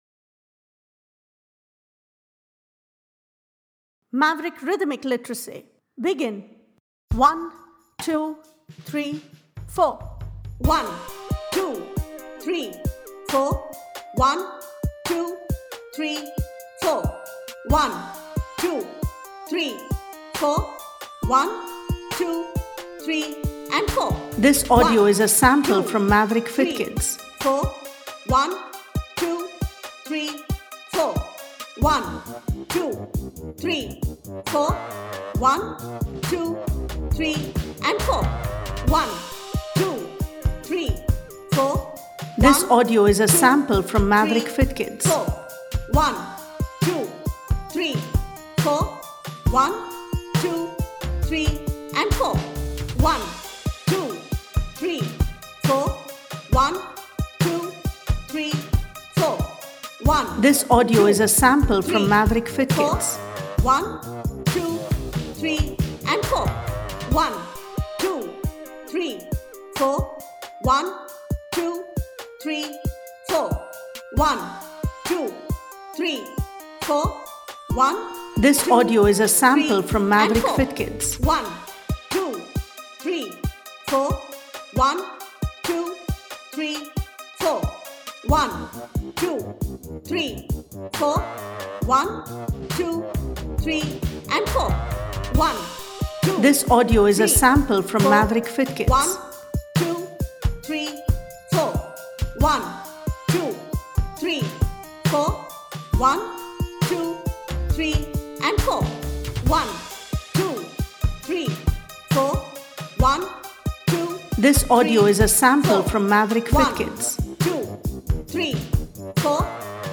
Aim for a consistent beat.
Single speed: